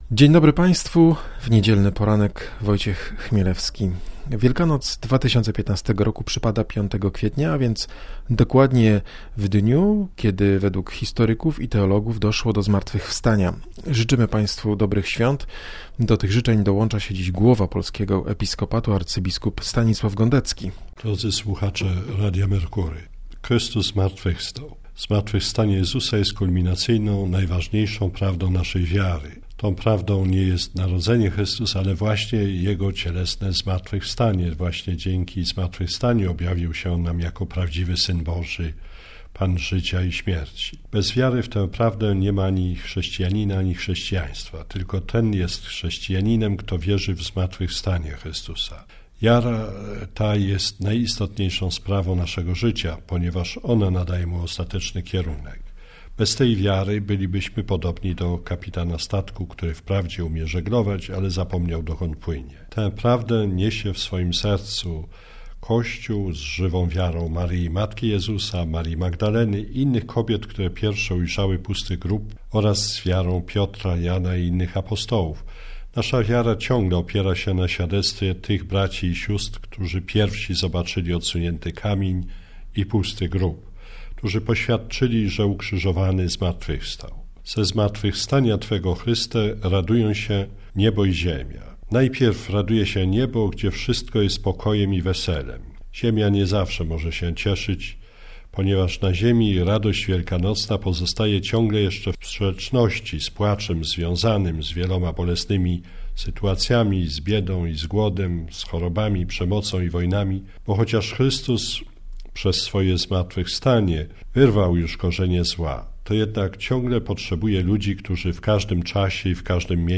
Wielkanocne życzenia przewodniczącego KEP - arcybiskupa Stanisława Gądeckiego.